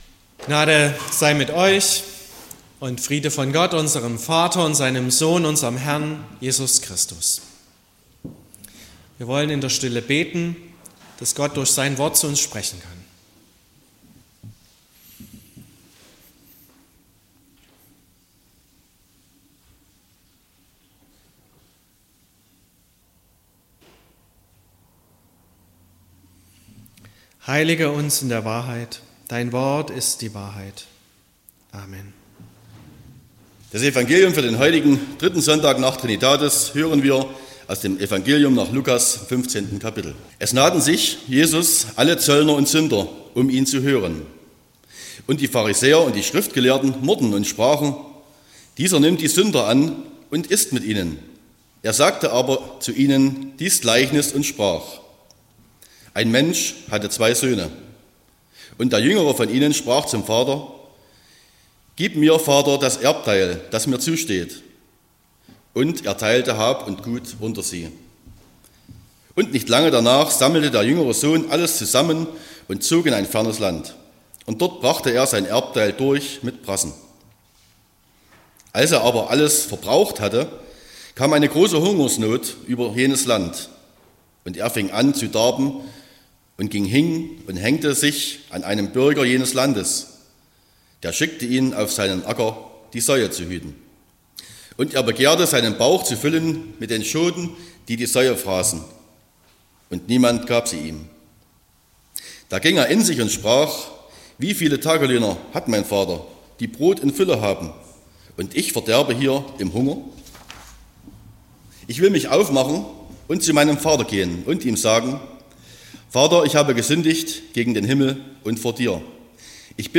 16.06.2024 – Gottesdienst
Predigt und Aufzeichnungen